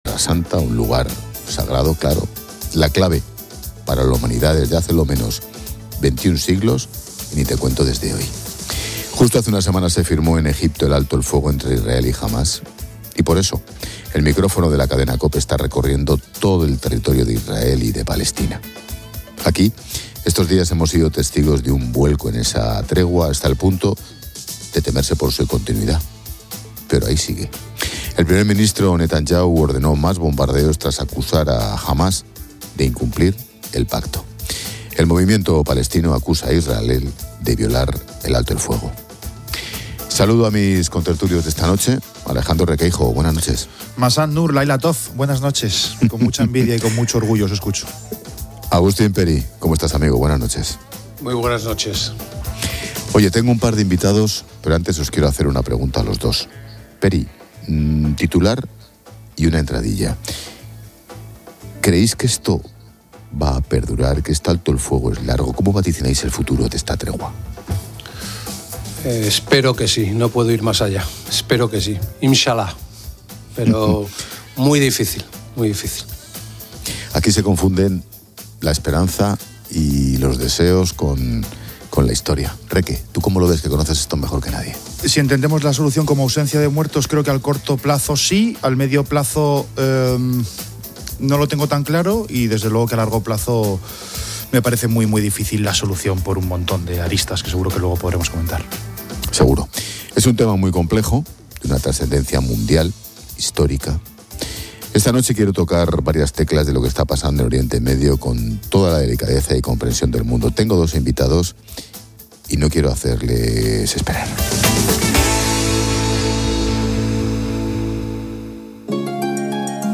COPE retransmite desde la frontera israelí-palestina tras el anuncio de un alto el fuego.